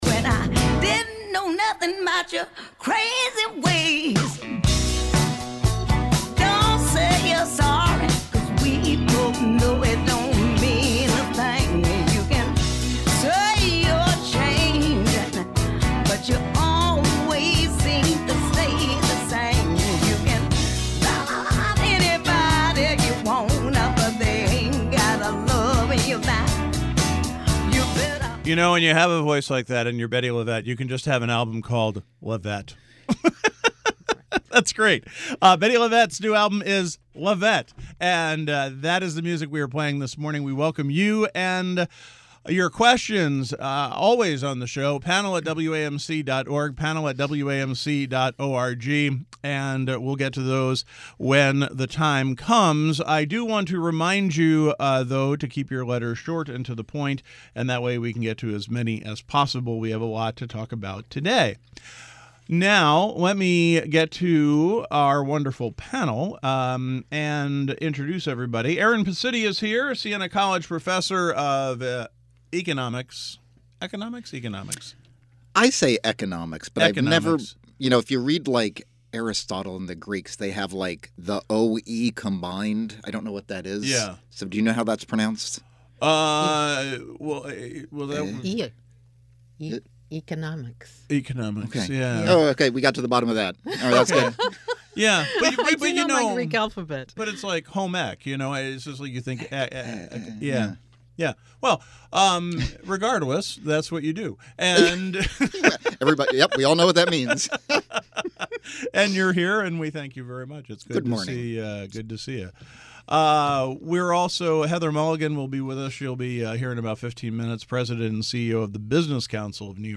The Roundtable Panel: a daily open discussion of issues in the news and beyond.